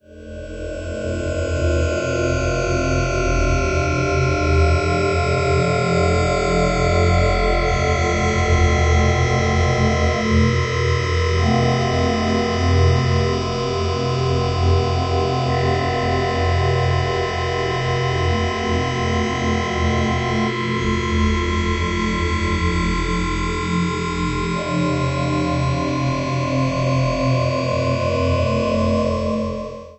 描述：使用SonicPhoto Gold.
Tag: img2snd 敢于-22 环境 无人驾驶飞机 气氛 超声处理